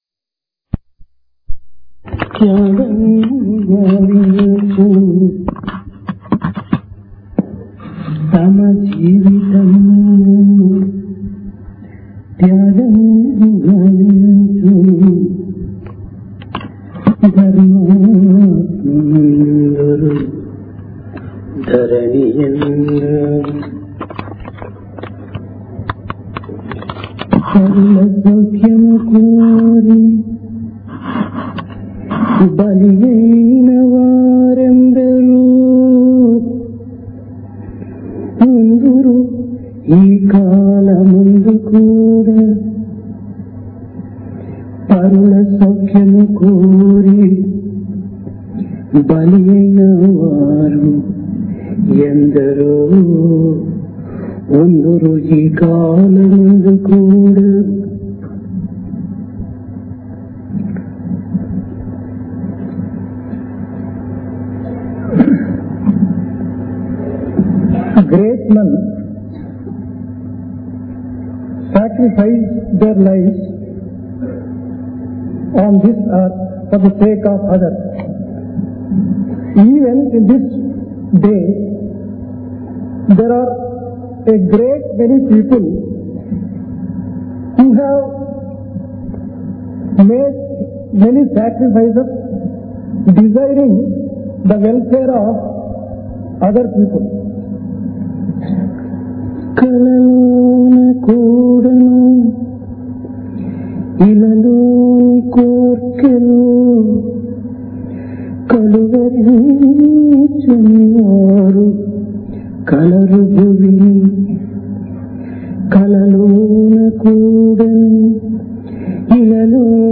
Divine Discourse of Bhagawan Sri Sathya Sai Baba
Place Prasanthi Nilayam Occasion Sankranthi, Sports Meet